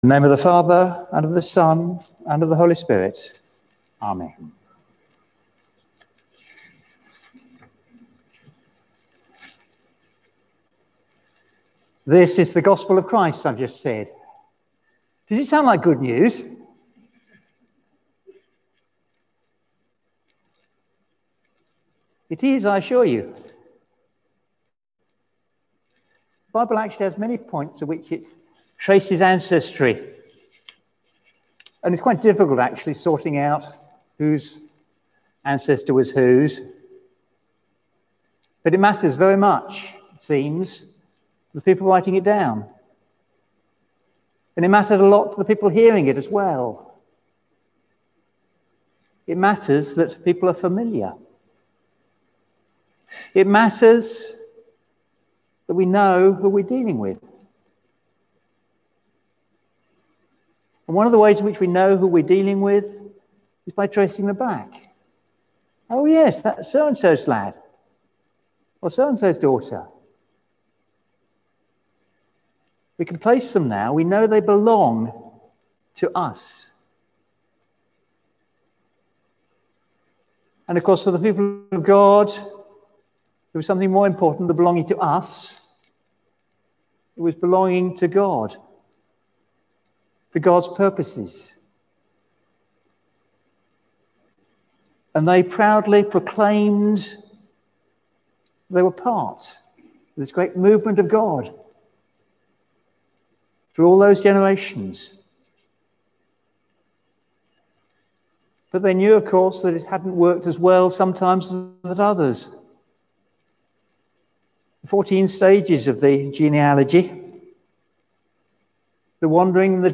Sermon: The Genealogy of Jesus | St Paul + St Stephen Gloucester